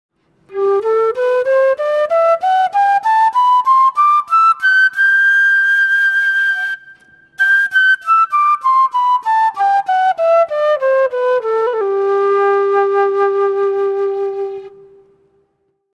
Кена Wari G Тональность: G
Достаточно отзывчивая модель кены, стабильно звучащая во всех трех октавах. Возможны незначительные отклонения по строю в верхнем диапазоне.
Кена - продольная флейта открытого типа, распространённая в южноамериканских Андах.